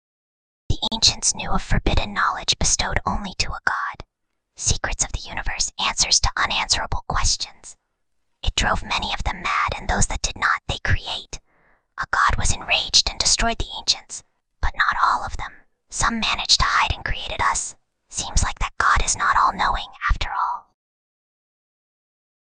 File:Whispering Girl 26.mp3
Whispering_Girl_26.mp3